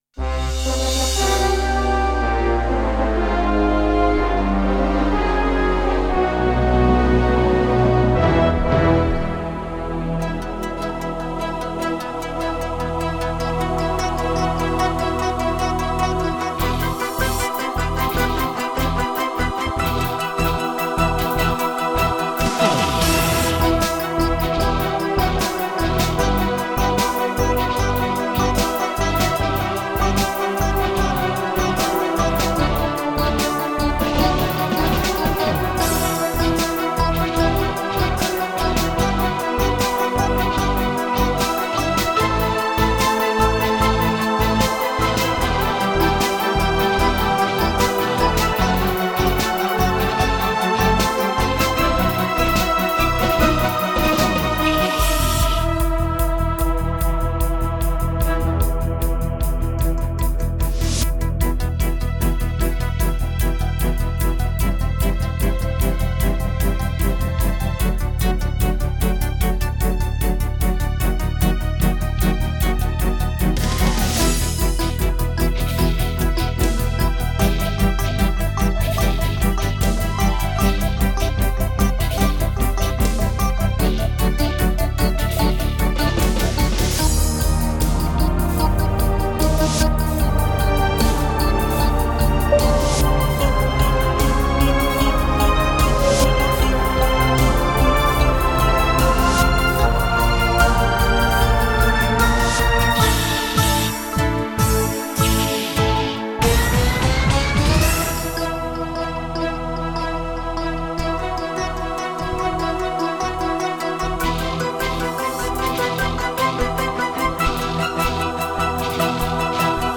instrumental album
but tending towards soundtrack and orchestral music.